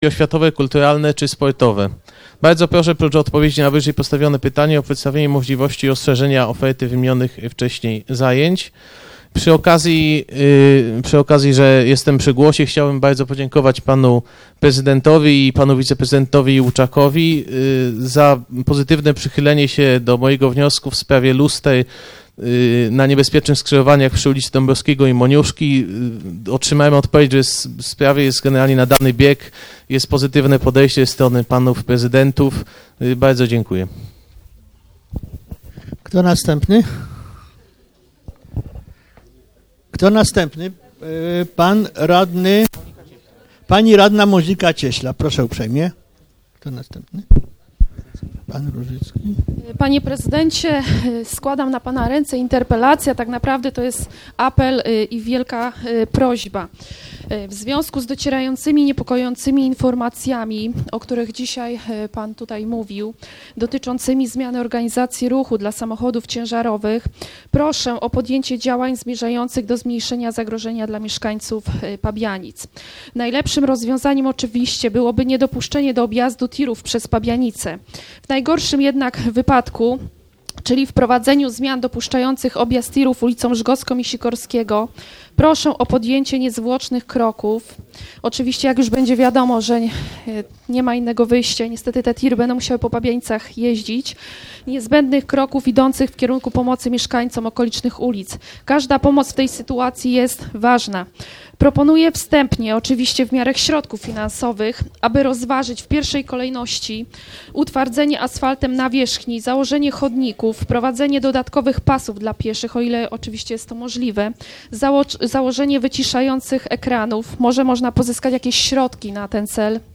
VII sesja Rady Miejskiej w Pabianicach - 26 lutego 2015 r. - 2015 rok - Biuletyn Informacji Publicznej Urzędu Miejskiego w Pabianicach